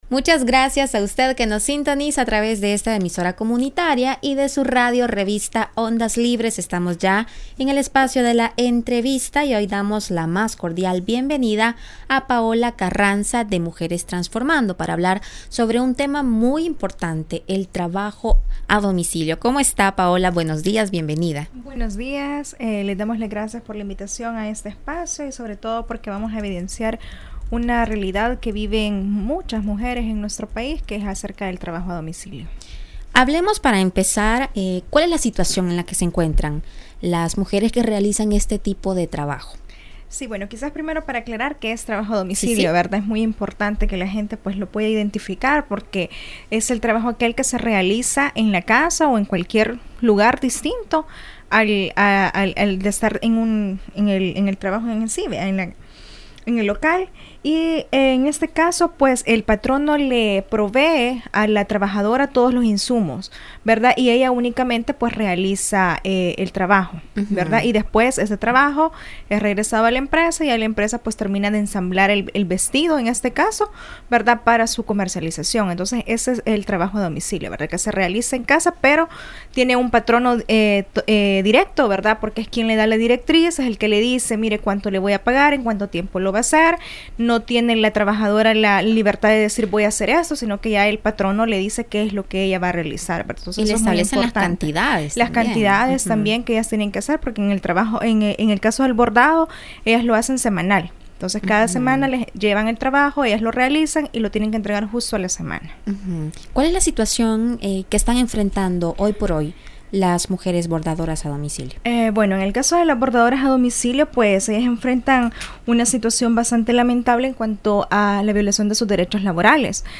entrevista televisiva